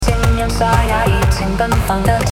声音沙哑热情奔放的